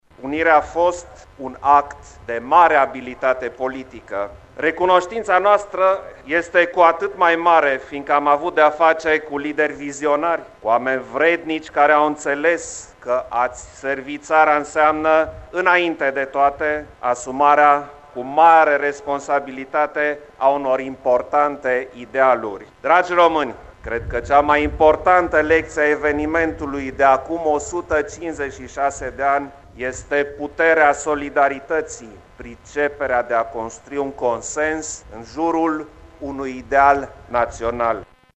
La eveniment participă şi preşedintele Klaus Johannis , care le-a adresat celor prezenţi – un mesaj de unitate şi solidaritate :